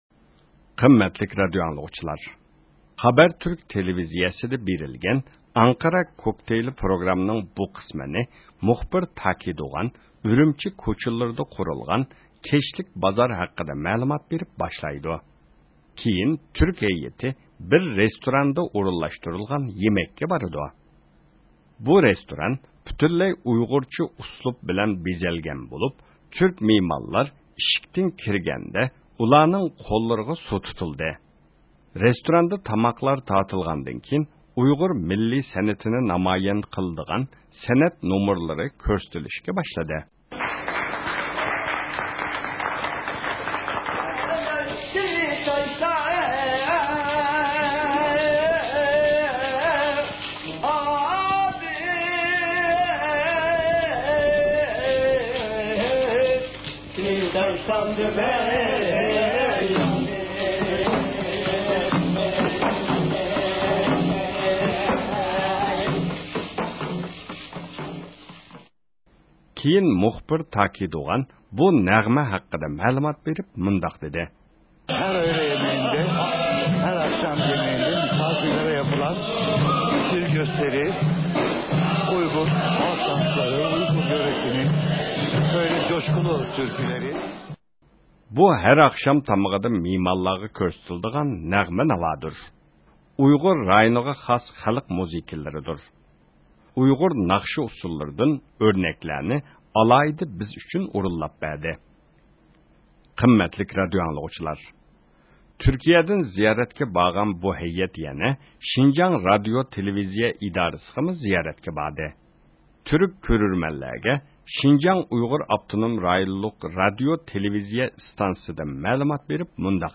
پروگراممىڭ بۇ قىسمى ئۈرۈمچىدە تارتىلغان بولۇپ، ۋەكىللەرنىڭ ئۈرۈمچىدىكى پائالىيەتلىرى تونۇشتۇرۇلغان.